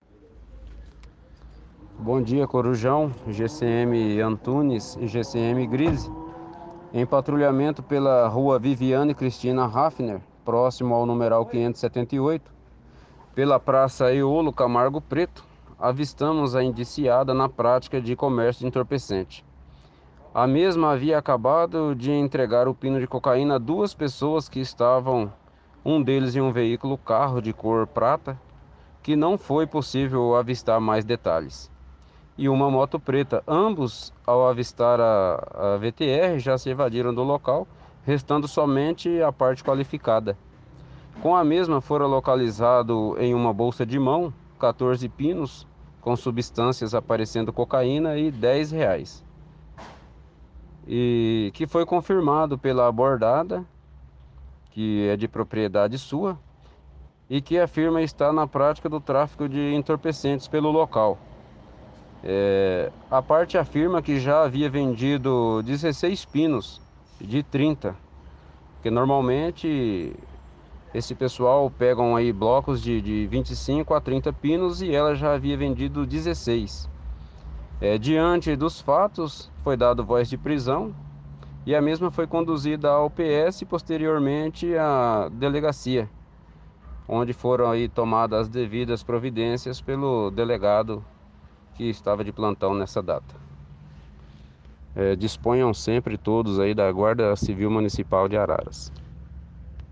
O guarda civil municipal